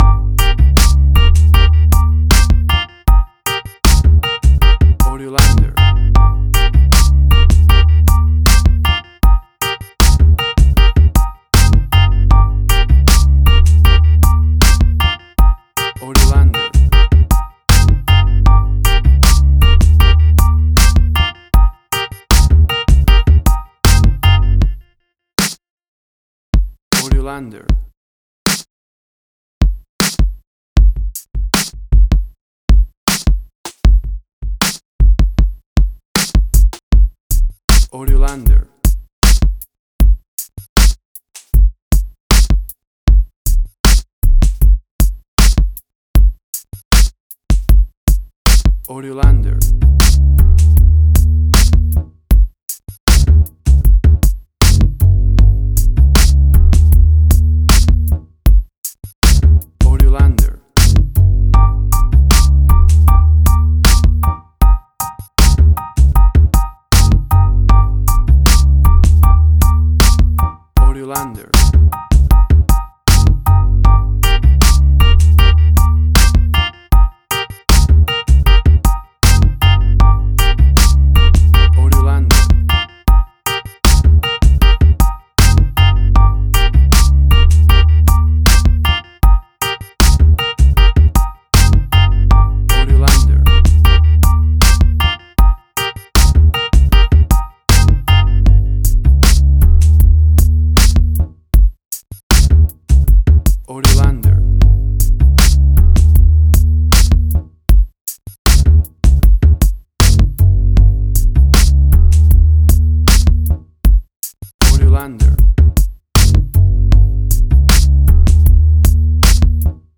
Tempo (BPM): 78